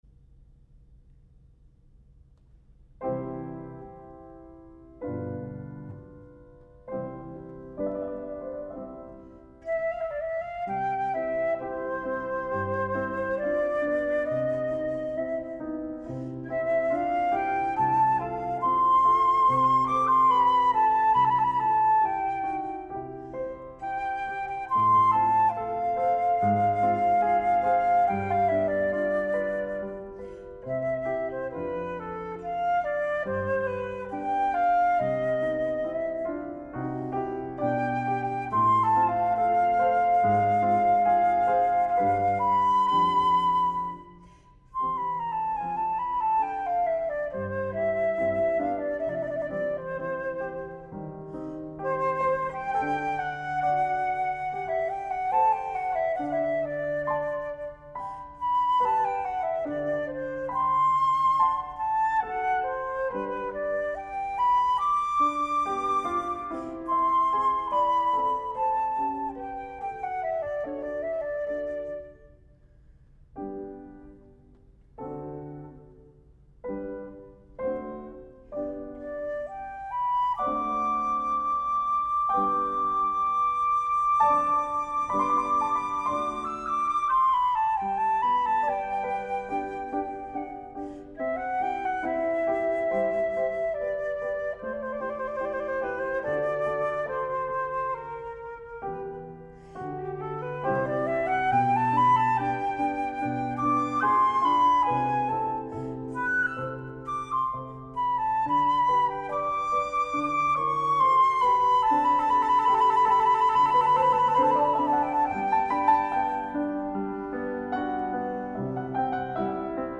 Freelance Musician